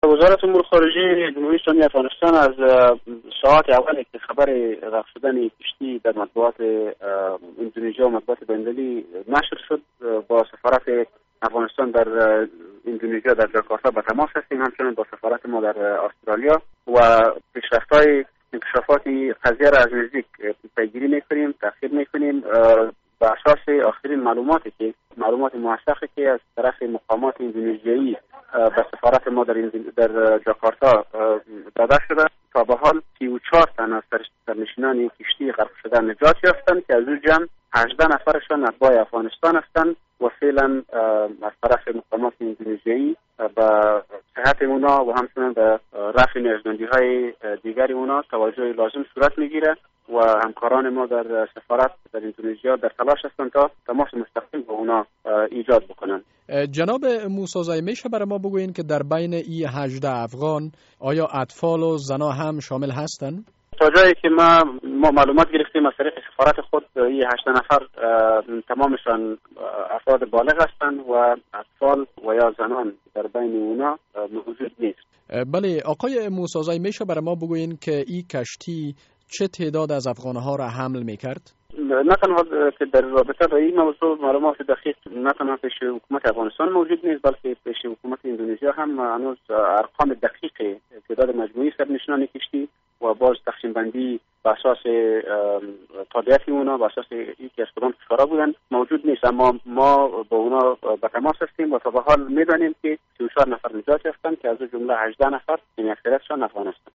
مصاحبه در مورد نجات سرنشینان کشتی که در اندونیزیا غرق شد